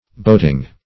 Boating \Boat"ing\, n.